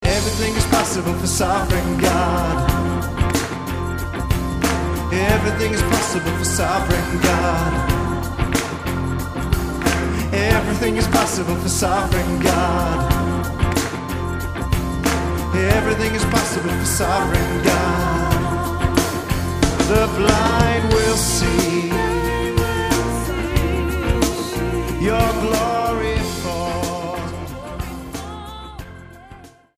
STYLE: Pop
warbling Hammond, a bright mix